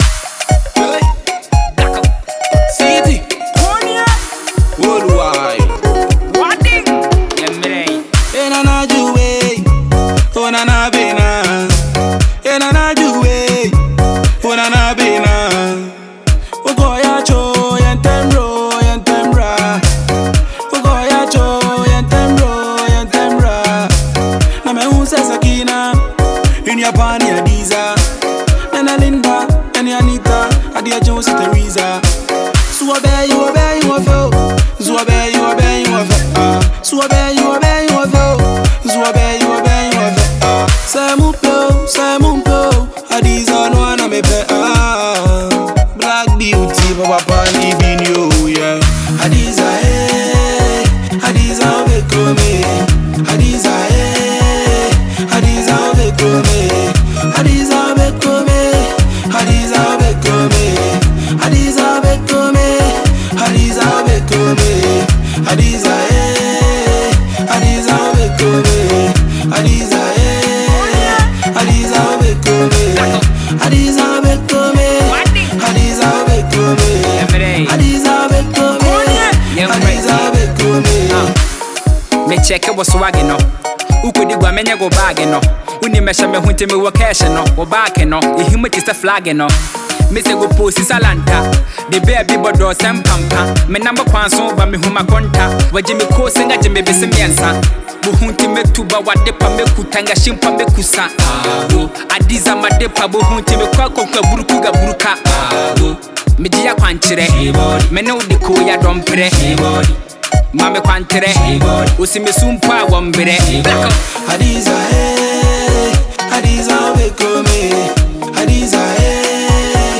is a soulful love song
With its infectious beat and heartfelt lyrics